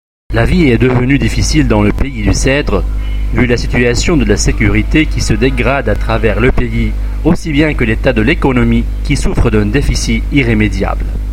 l’hymne national libanais en style rock